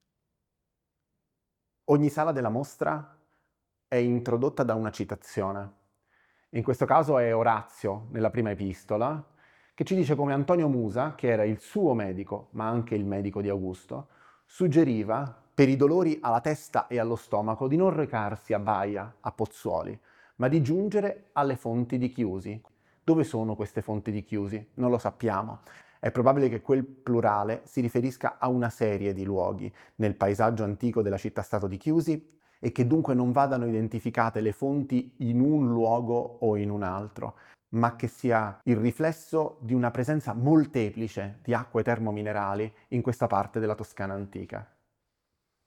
L’audioguida dedicata alla mostra “Gli Dei ritornano. I Bronzi di San Casciano”